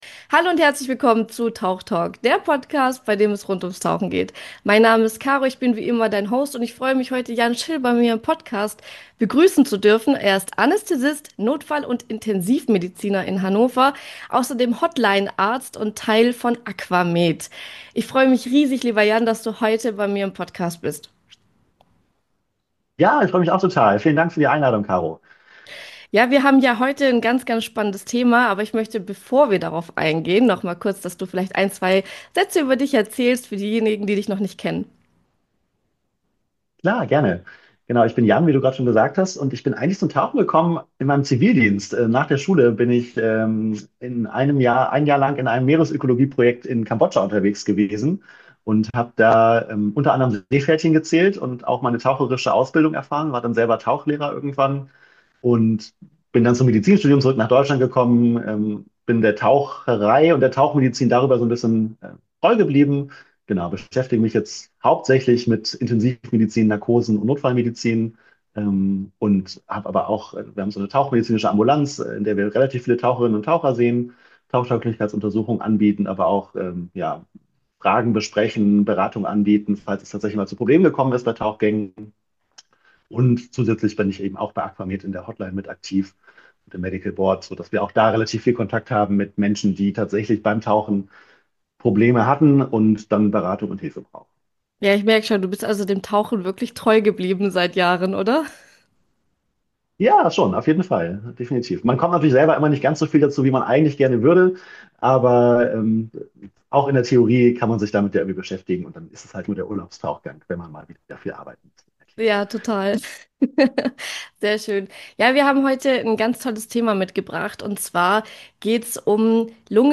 Ein tiefgehendes Gespräch über Sicherheit, Körperbewusstsein und Respekt vor der Tiefe.